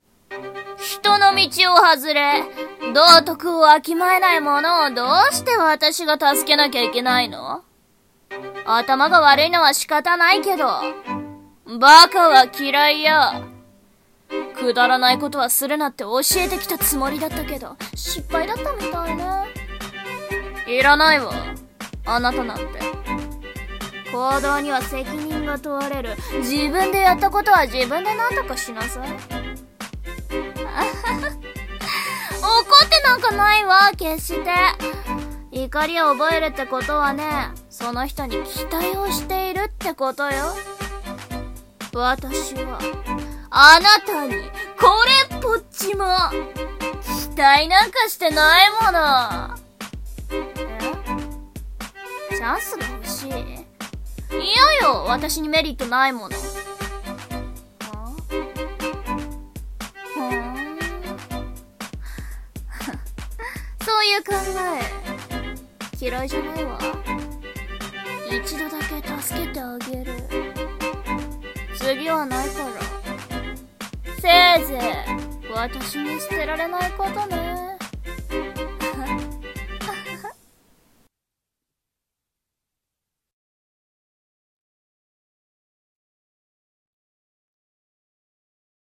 【声劇】期待なんか